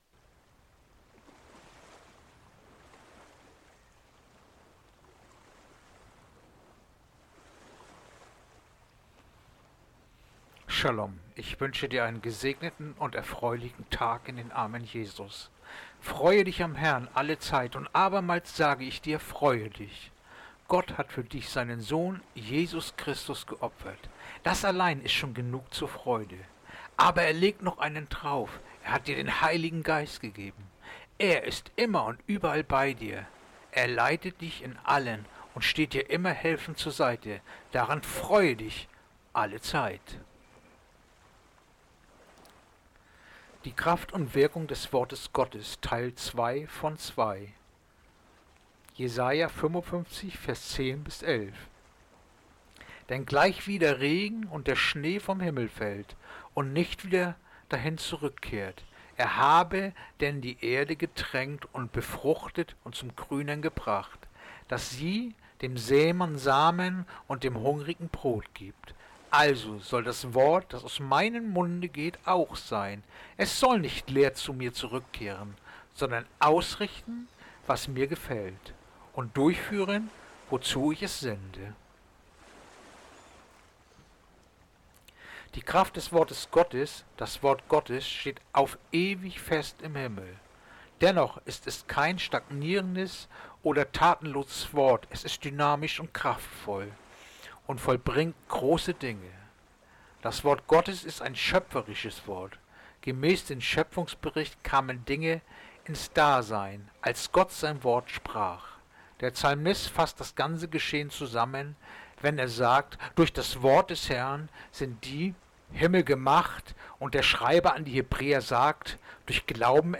Andacht-vom-04-Juli-Jesaja-55-10-11-Teil-2-von-2